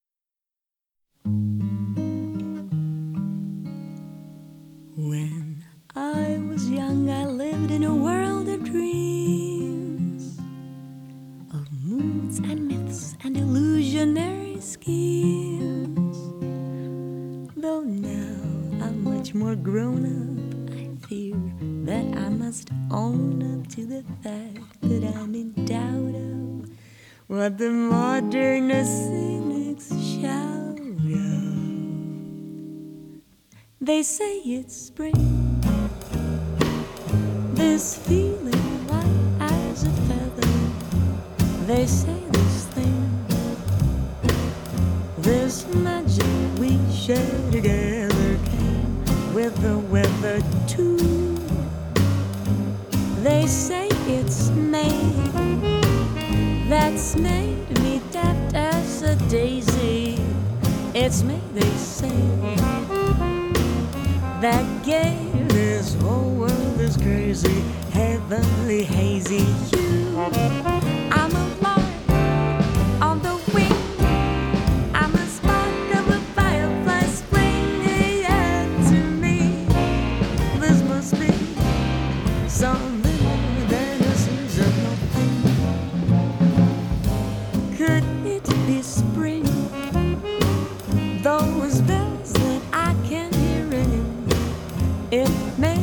Accordion
Vocals
Guitar
Double Bass
Drums